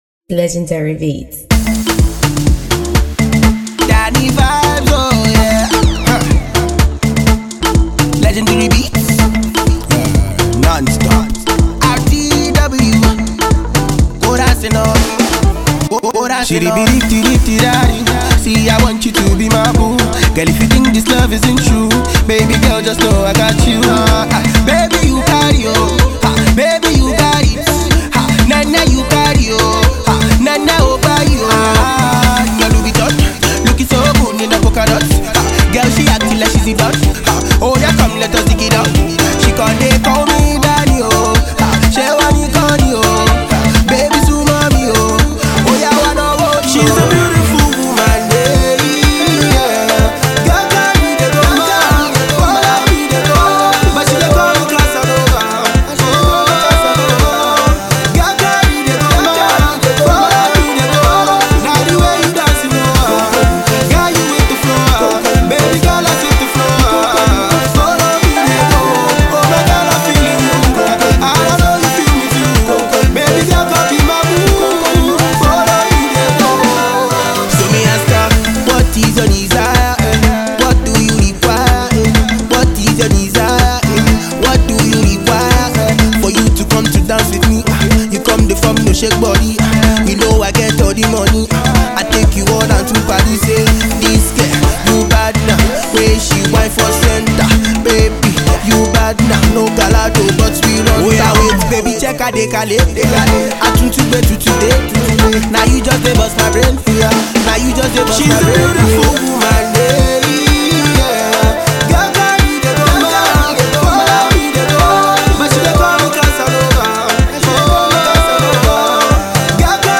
high octane track